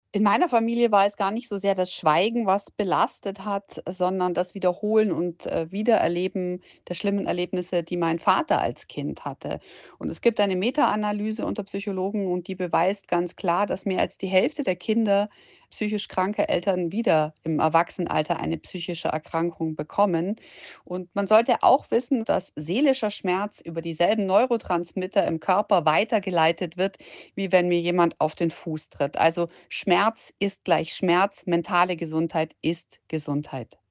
Wir sprechen mit Caro Matzko, Journalistin und TV-Moderatorin.